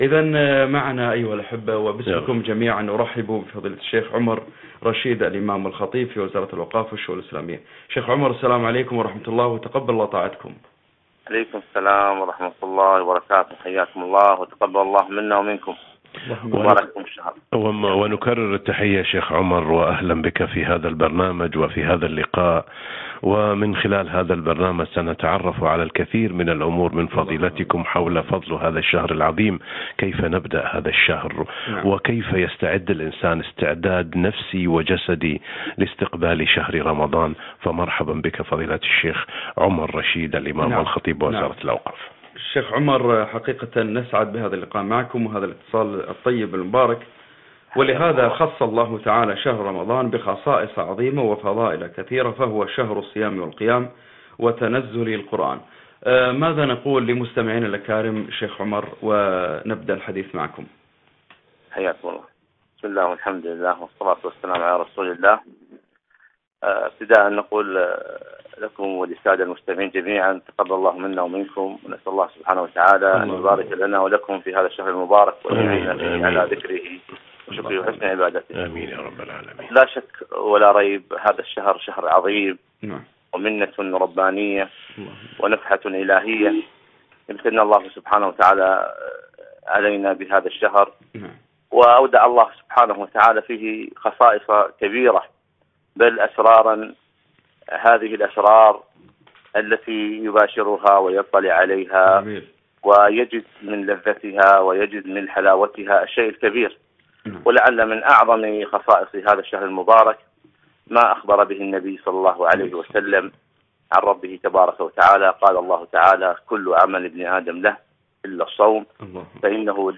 لقاء إذاعي - برنامج واحة الإيمان - رمضان